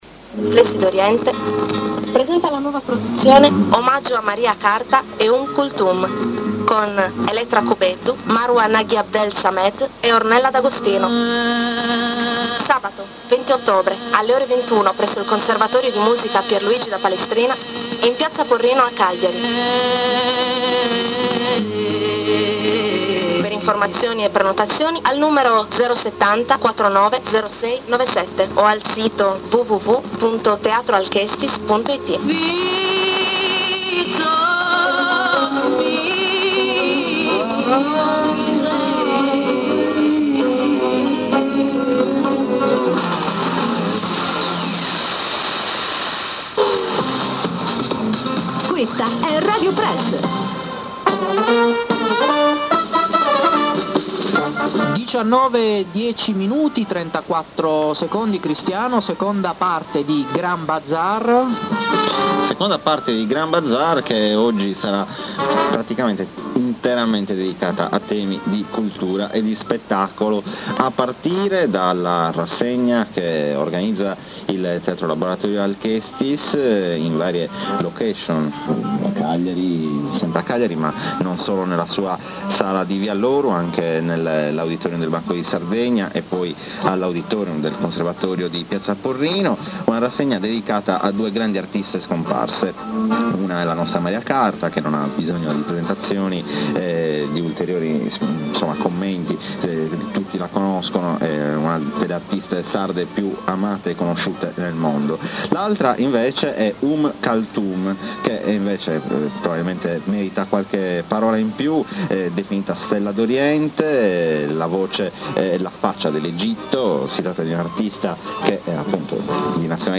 Voci dal Mediterraneo. Riflessi d’Oriente (intervista a Radio Press)